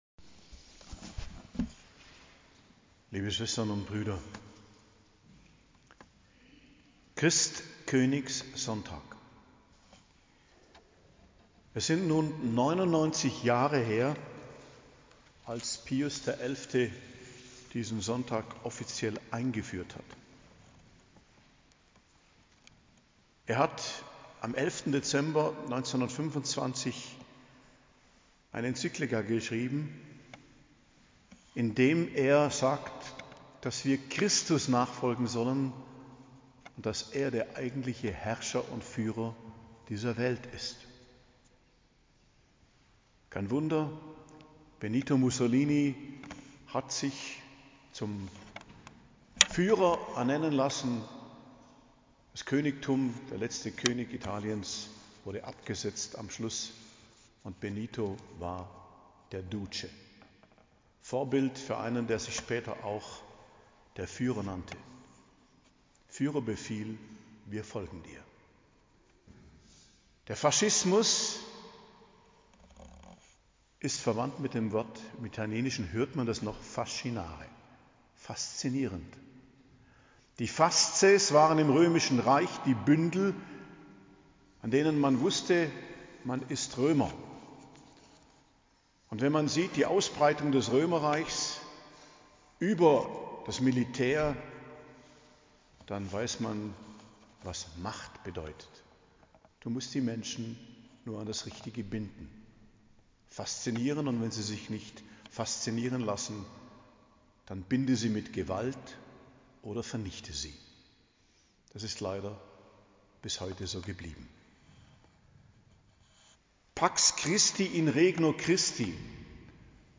Predigt zum Christkönigssonntag, 24.11.2024 ~ Geistliches Zentrum Kloster Heiligkreuztal Podcast